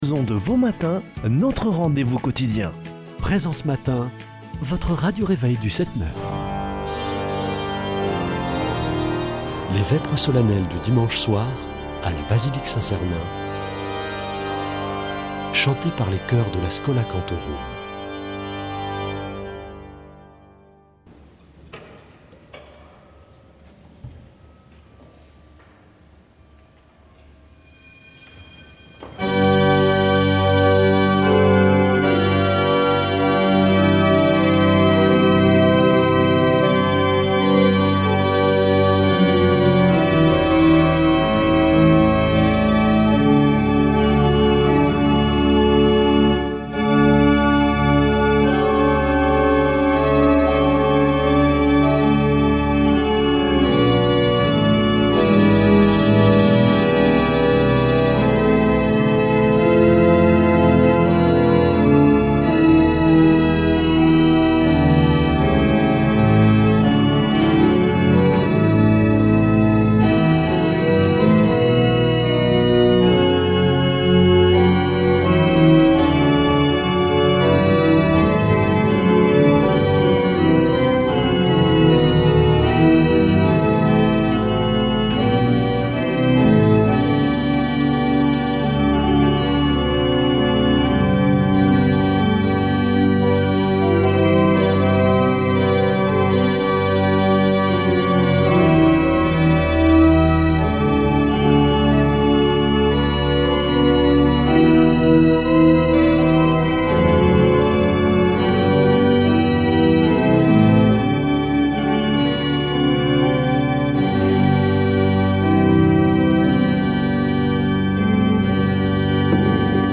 Une émission présentée par Schola Saint Sernin Chanteurs